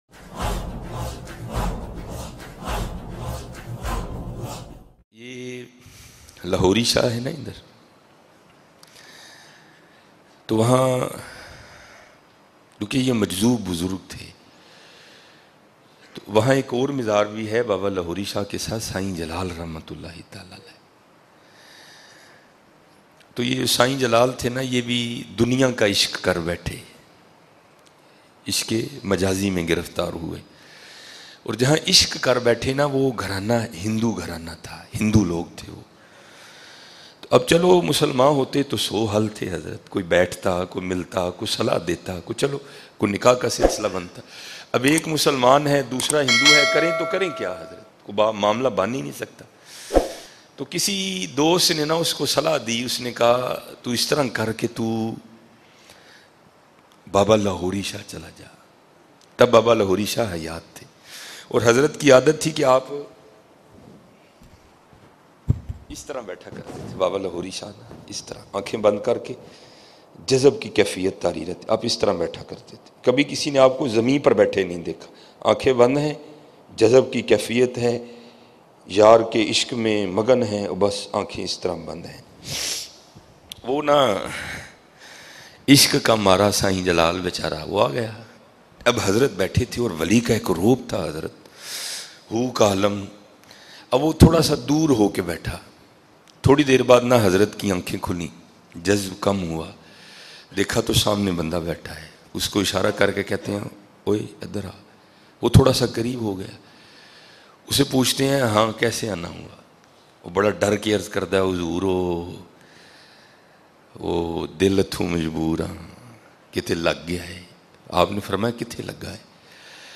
Ishaq e mizaji ya Ishaq Haqeqi Emotional Bayan mp3